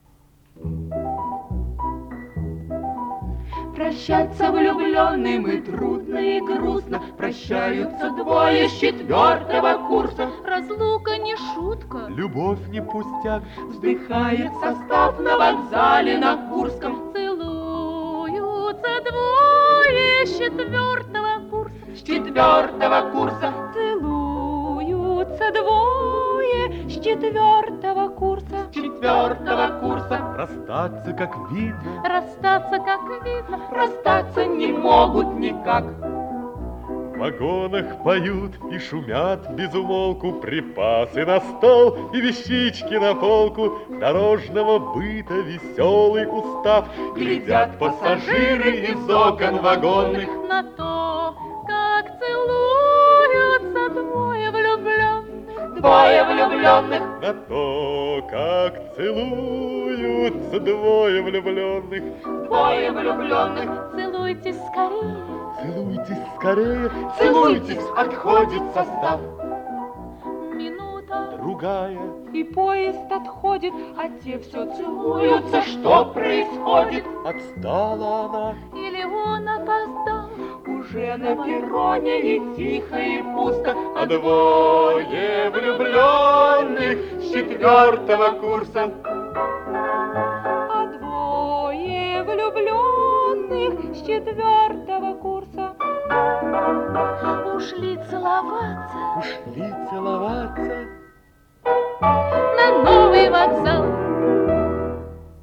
По-моему,один из мужских голосов - баритон.
И вообще, это же трио (2 девушки, 1 мужчина), так?